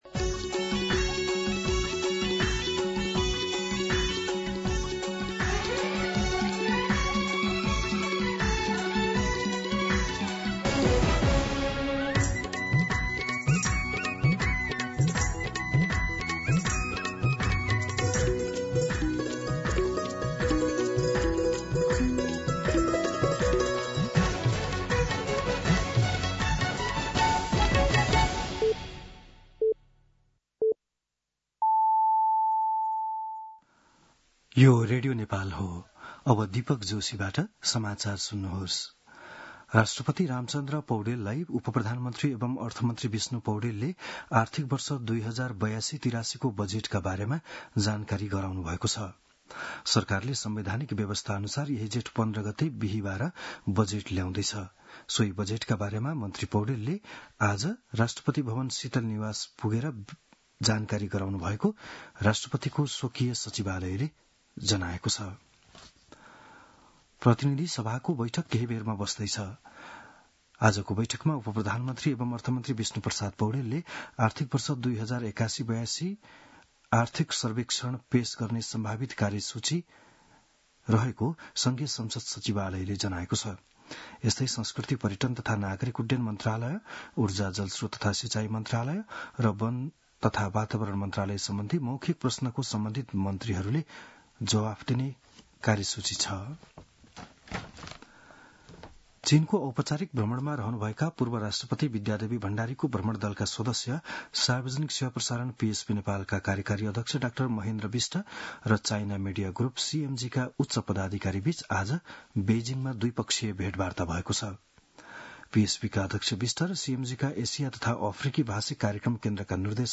बिहान ११ बजेको नेपाली समाचार : १३ जेठ , २०८२
11am-News-13.mp3